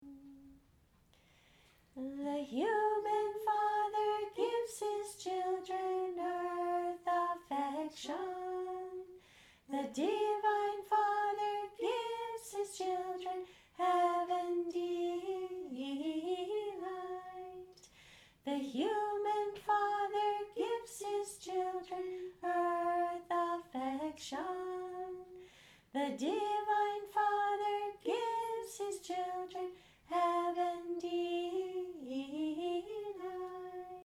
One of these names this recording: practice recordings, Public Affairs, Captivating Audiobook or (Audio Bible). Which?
practice recordings